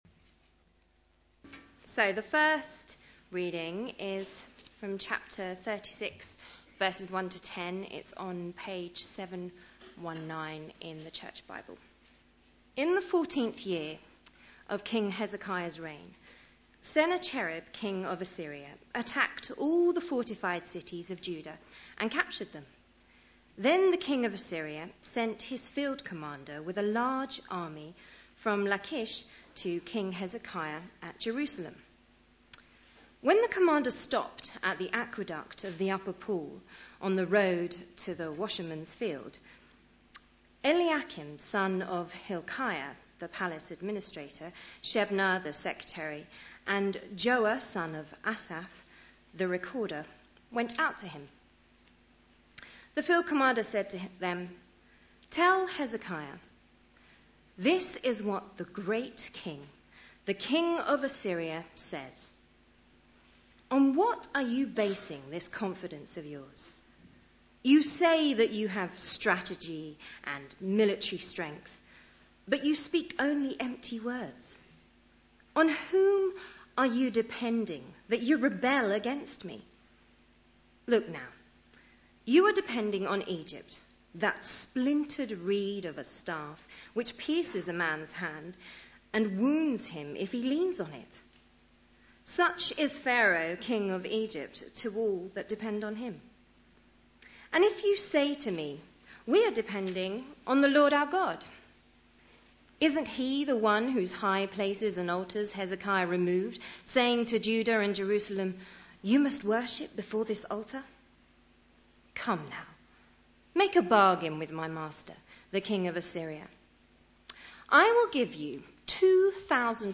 Media for Sunday Service
Sermon